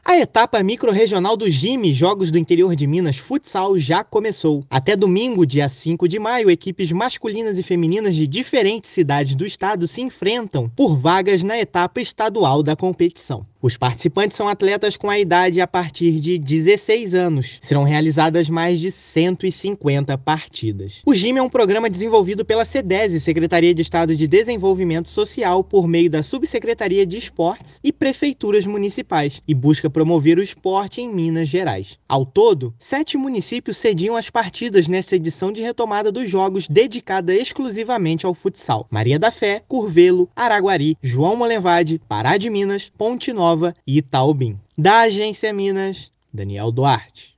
Equipes disputam mais de 150 partidas pela Etapa Microrregional do Jimi Futsal entre 30/4 e 5/5. Ouça matéria de rádio.